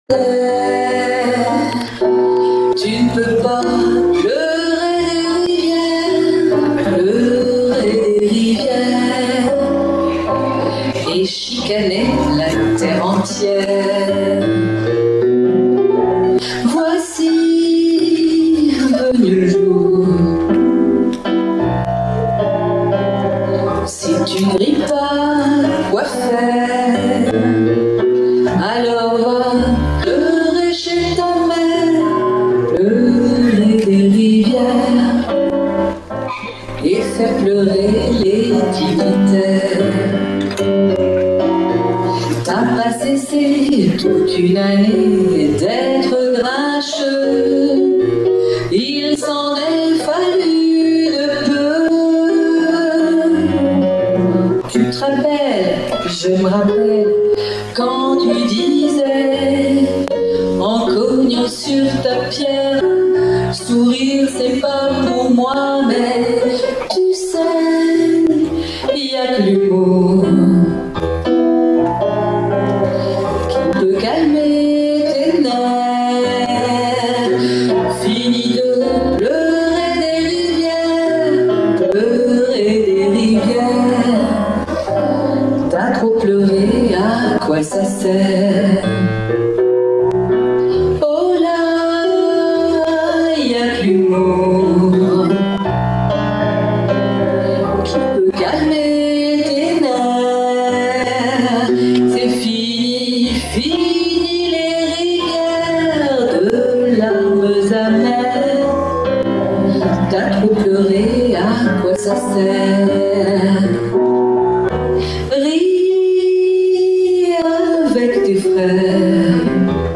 Chansons maçonneuses
Extrait, enregistrement public